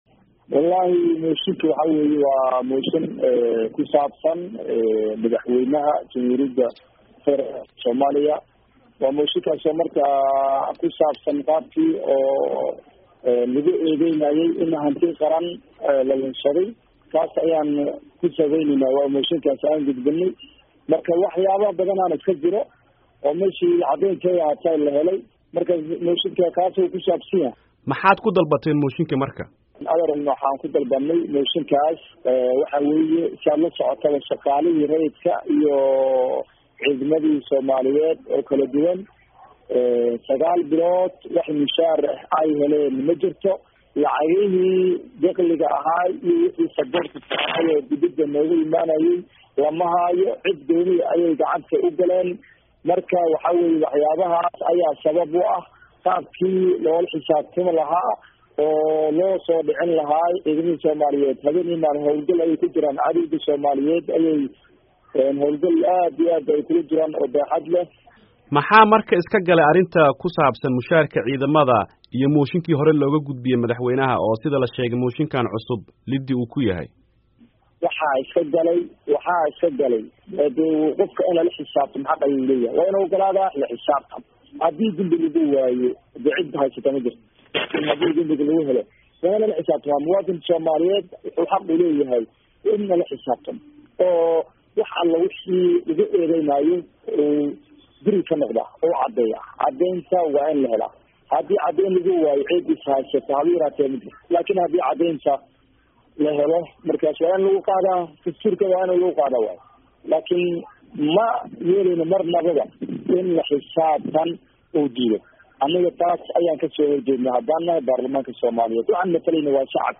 Wareysi: Xildhibaan Abubakar